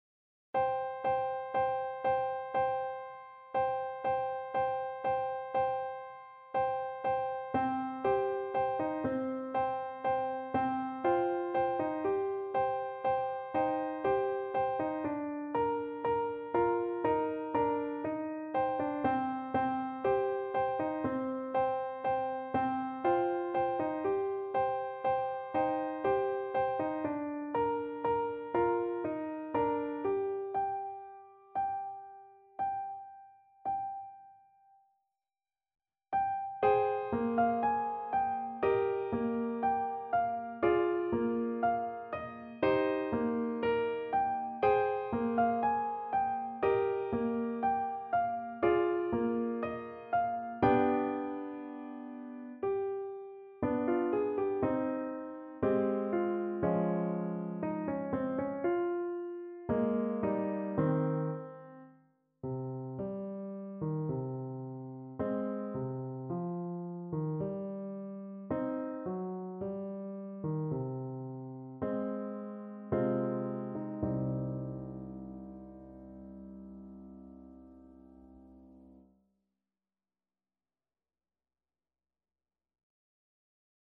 No parts available for this pieces as it is for solo piano.
3/4 (View more 3/4 Music)
C minor (Sounding Pitch) (View more C minor Music for Piano )
Andante sostenuto =60
Piano  (View more Easy Piano Music)
Classical (View more Classical Piano Music)